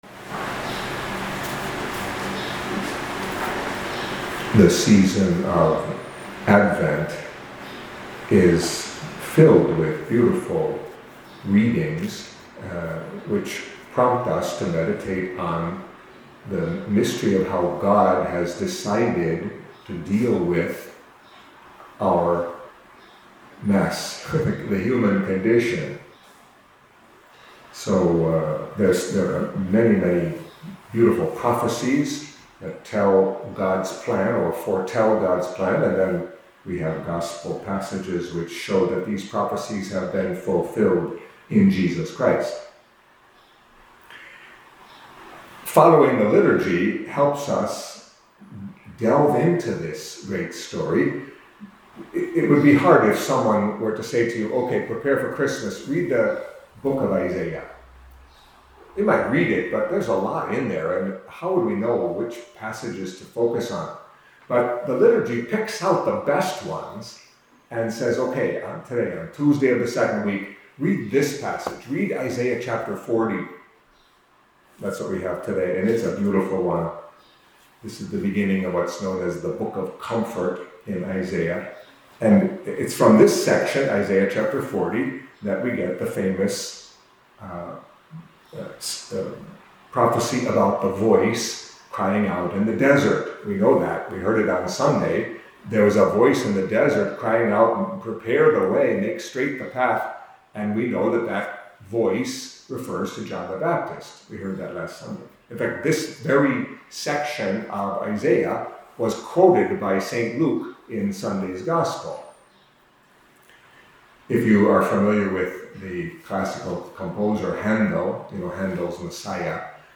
Catholic Mass homily for Tuesday of the 2nd Week of Advent